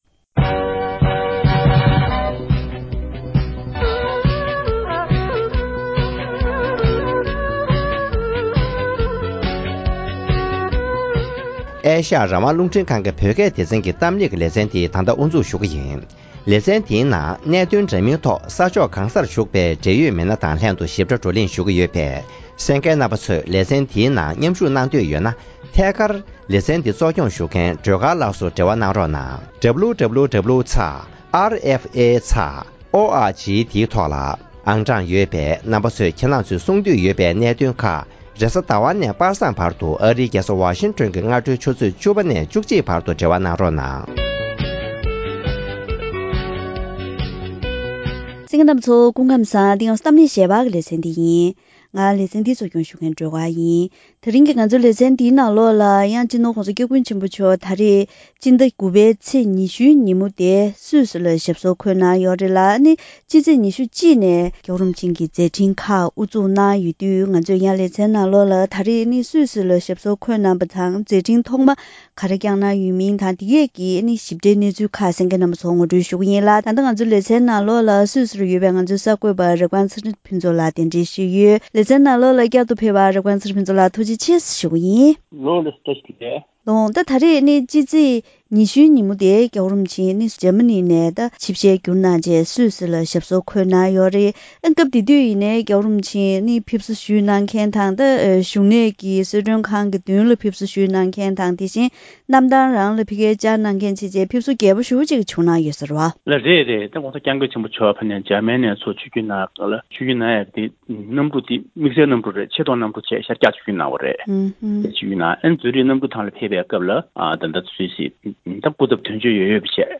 ༄༅། །གཏམ་གླེང་ཞལ་པར་ལེ་ཚན་ནང་སྤྱི་ནོར་༧གོང་ས་སྐྱབས་མགོན་ཆེན་པོ་མཆོག་སུད་སིར་ཞབས་སོར་བདེ་བར་འཁོད་པ་དང་གསར་འགོད་ལྷན་ཚོགས་སྐོང་ཚོགས་གནང་བ།